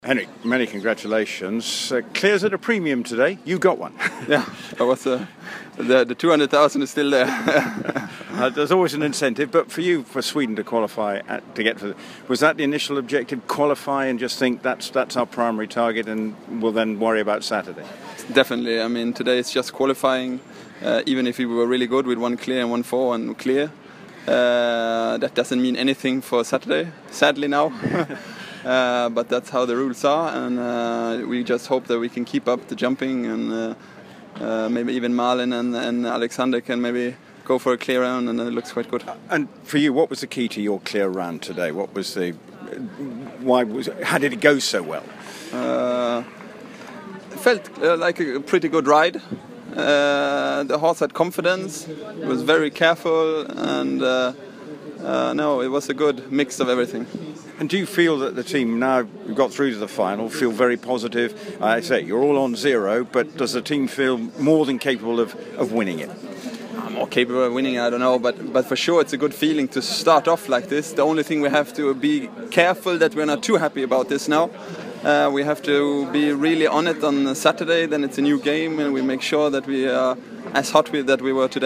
FEI Henrik Von Eckermann rd 1 Barcelona Audio to download courtesy of the FEI Interview with Swedish rider Henrik Von Eckermann Henrik Von Eckermann was one of 2 Swedish riders who jumped clear rounds to help secure Sweden's place in the 8 team final of the Furusiyya FEI Nations cup jumping competition in Barcelona.